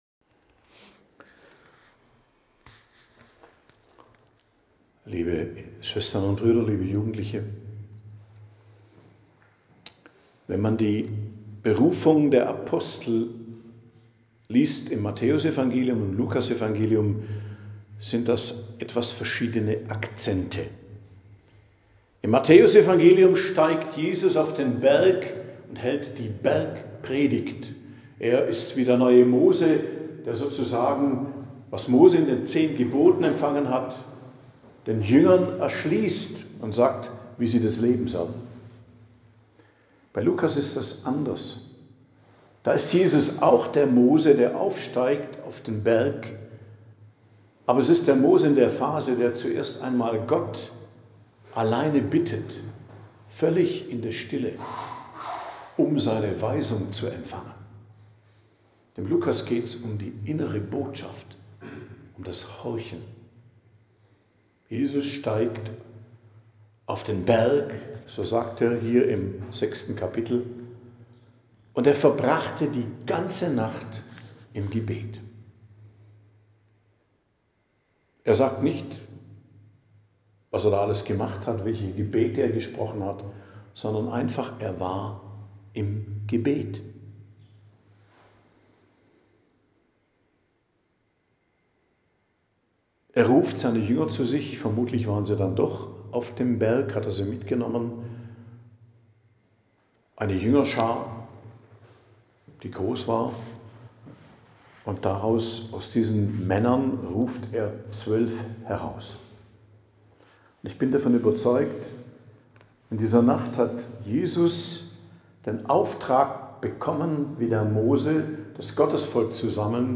Predigt am Fest der Hll Simon und Judas, Apostel, 28.10.2025 ~ Geistliches Zentrum Kloster Heiligkreuztal Podcast